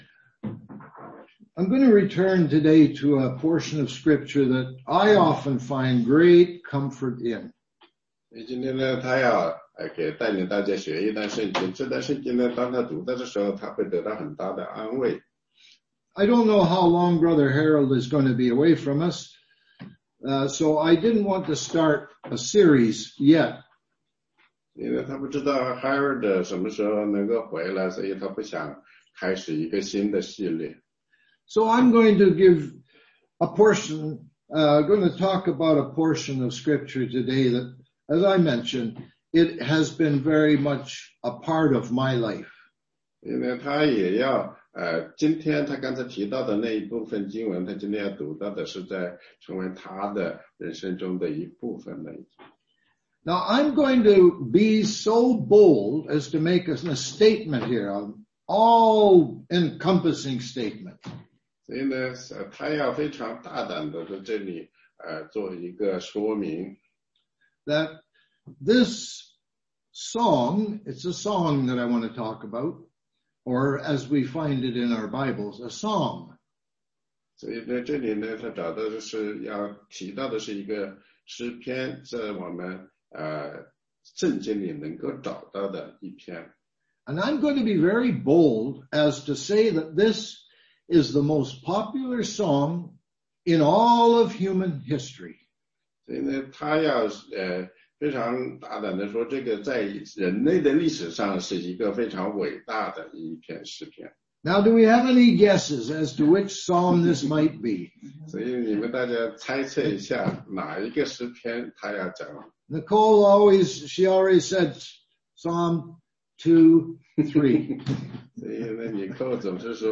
16街讲道录音 - 其它